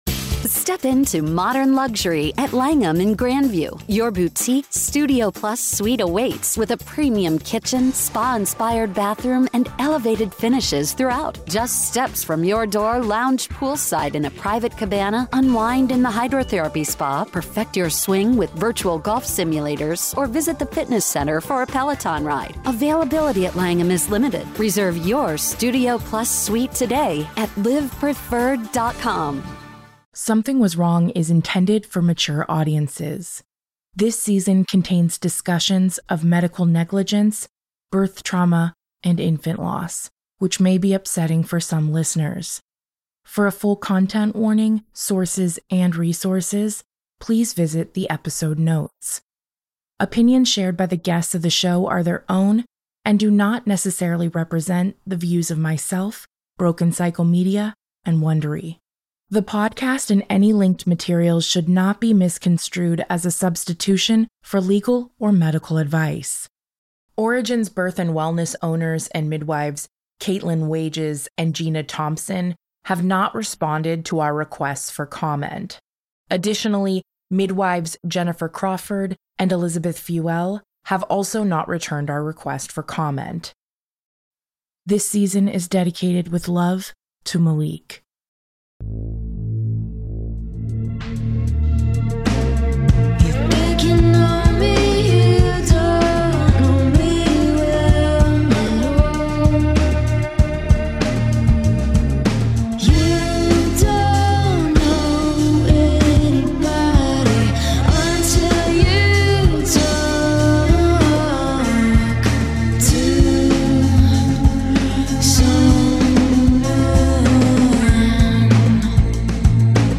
This season features powerful firsthand accounts from both survivors and experts, mirroring the broader crisis in U.S. maternal healthcare and its devastating effects on marginalized communities.